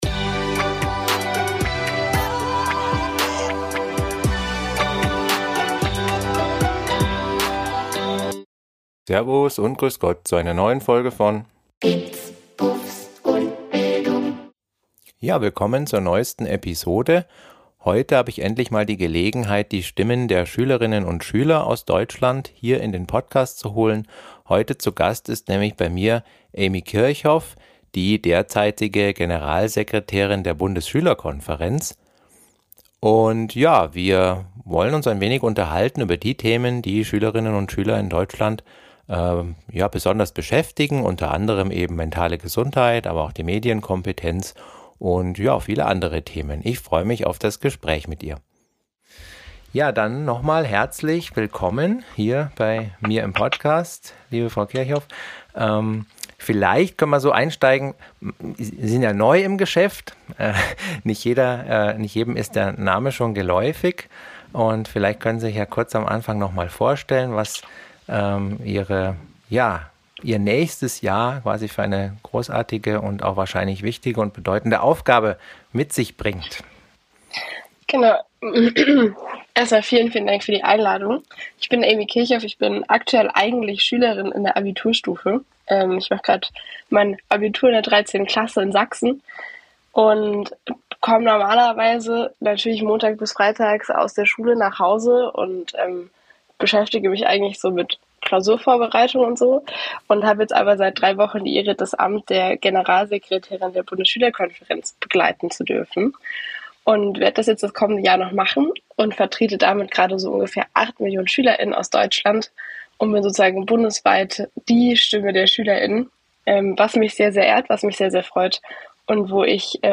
#46 Episode 46 IM GESPRÄCH